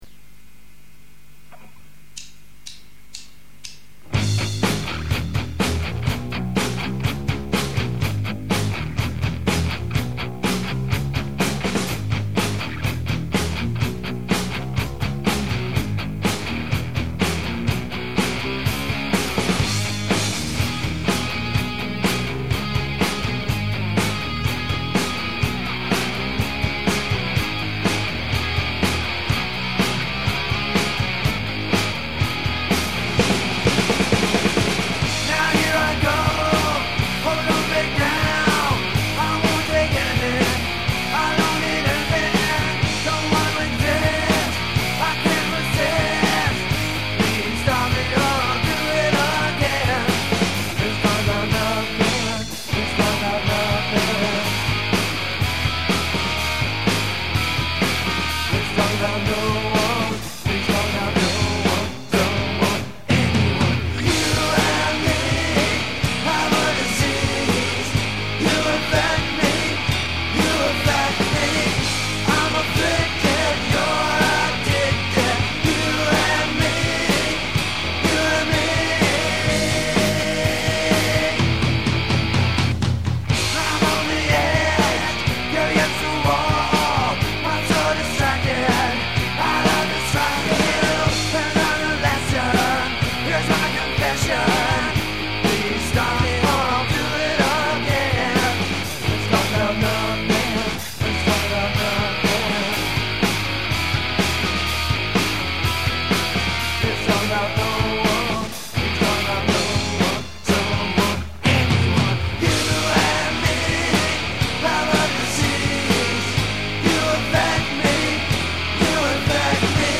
home demos